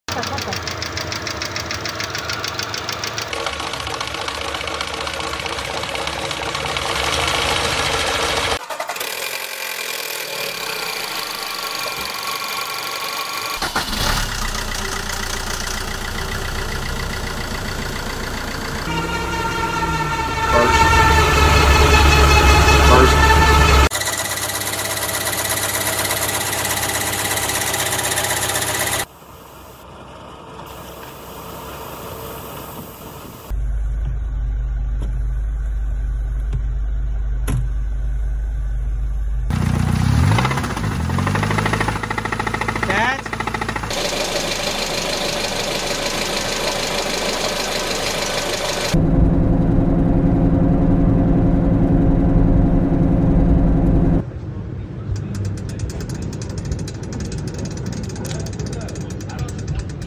Car problems sounds part 10 sound effects free download
car problem sounds engine noise issues
strange car noises